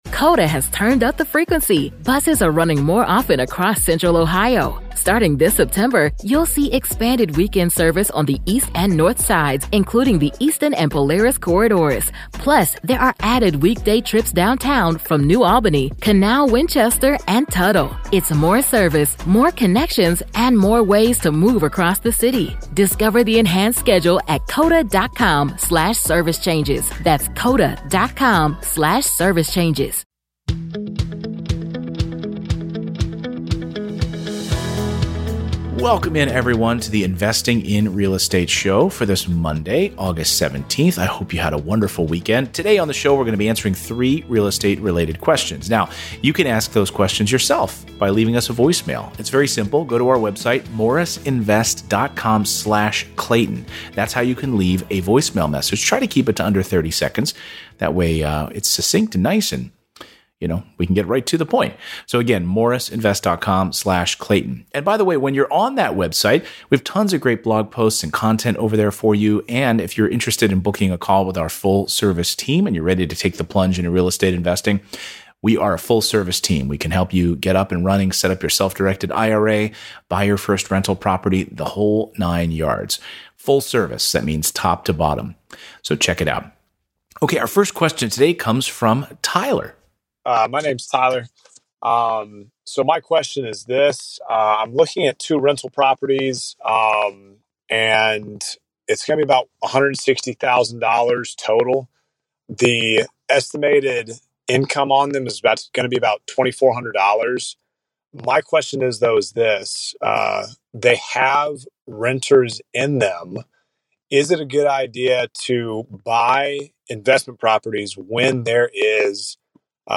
Today you're going to hear three listener questions regarding inherited tenants, how to appropriately calculate your Freedom Number, and the BRRRR Method.